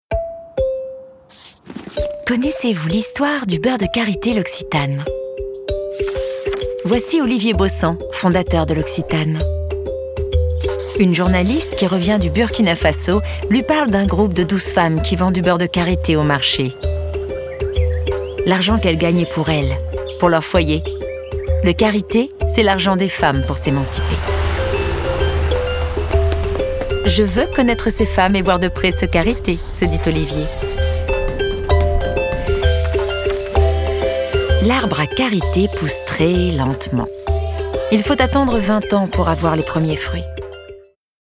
narrative // souriante